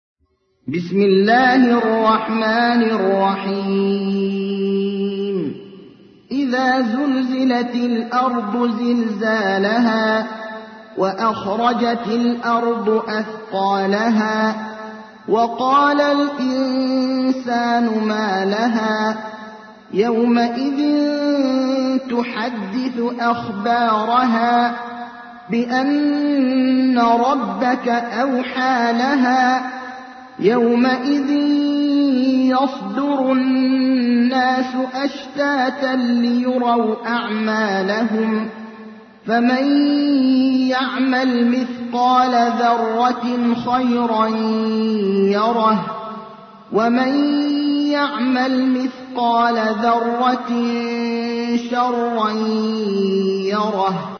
تحميل : 99. سورة الزلزلة / القارئ ابراهيم الأخضر / القرآن الكريم / موقع يا حسين